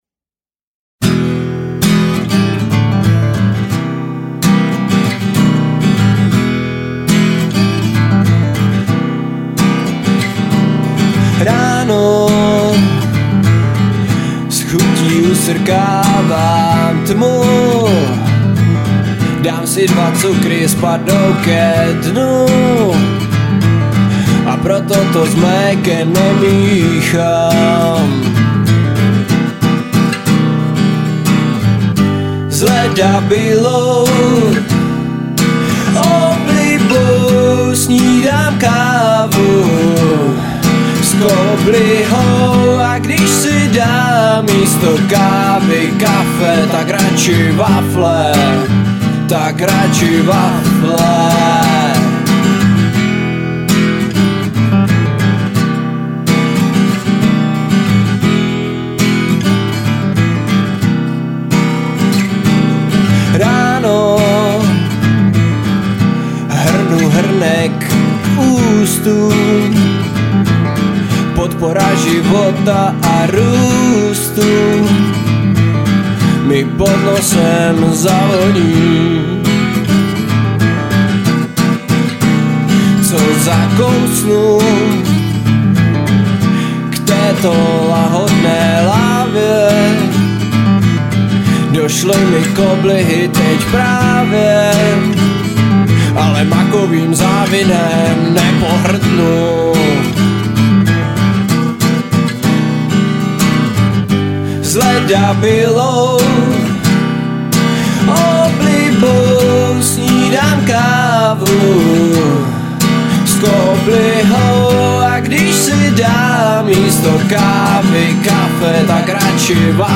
Žánr: Indie/Alternativa
Byly nahrány v jednom obýváku v Blansku